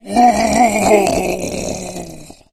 fracture_attack_0.ogg